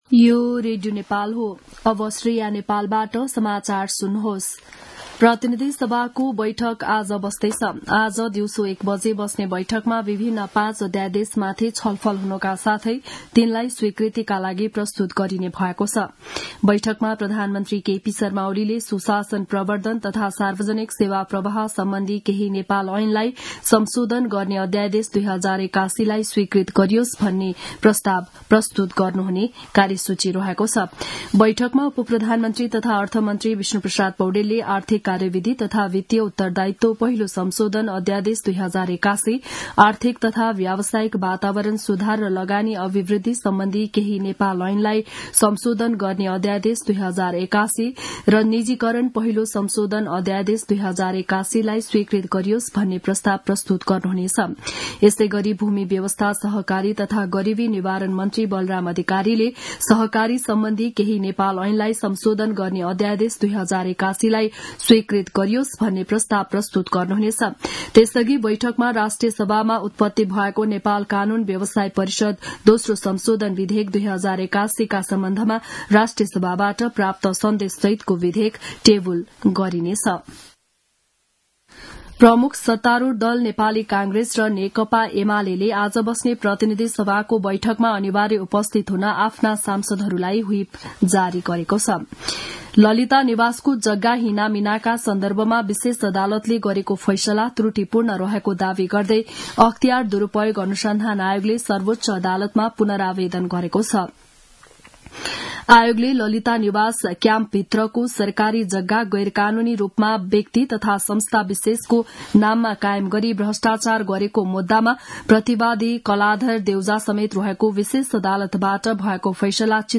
बिहान ११ बजेको नेपाली समाचार : २२ फागुन , २०८१
11-am-news-1-2.mp3